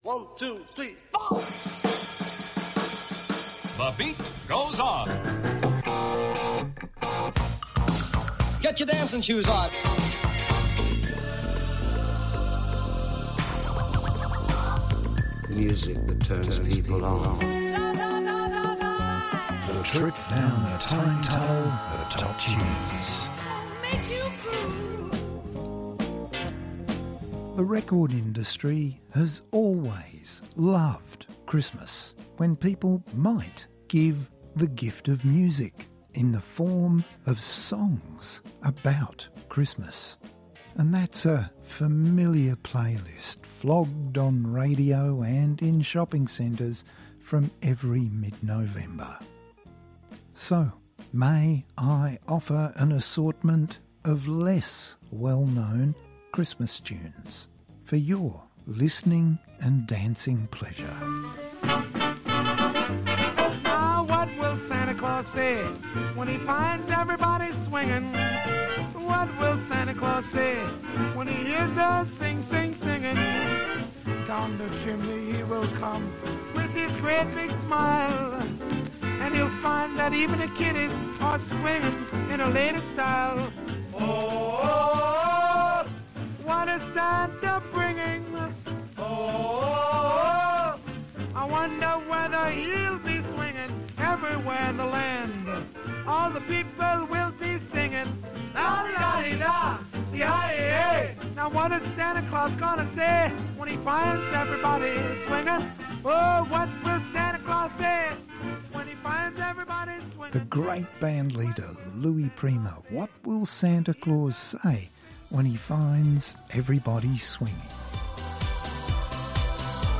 festive assortment
Indie band